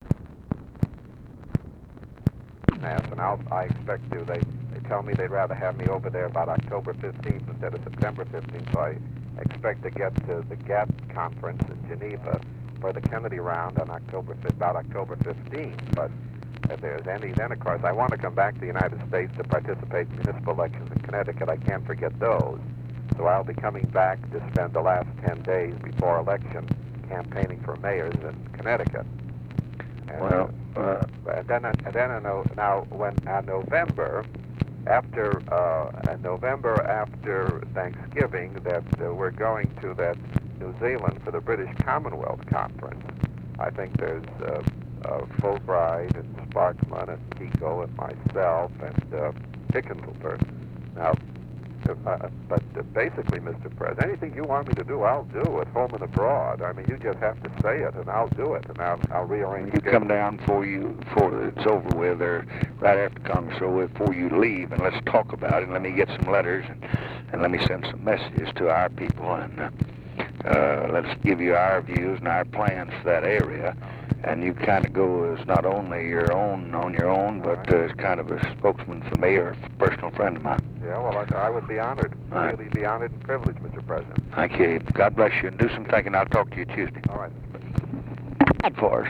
Conversation with ABRAHAM RIBICOFF, September 1, 1965
Secret White House Tapes